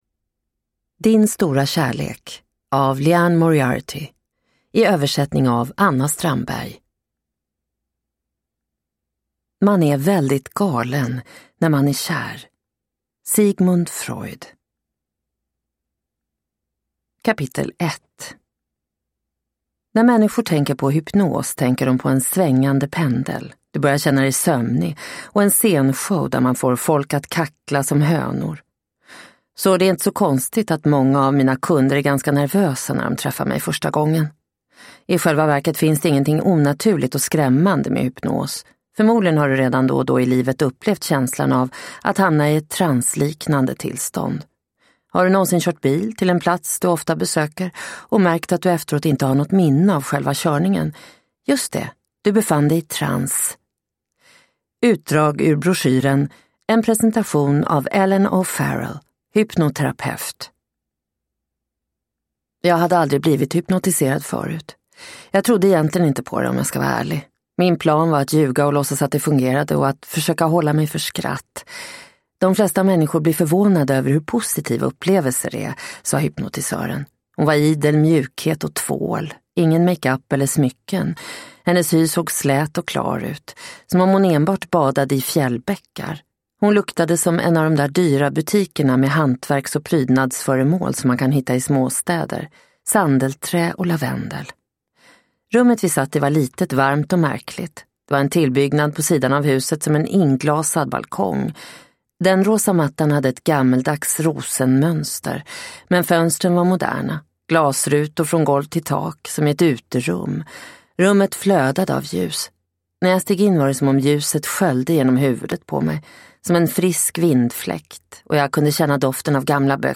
Din stora kärlek – Ljudbok – Laddas ner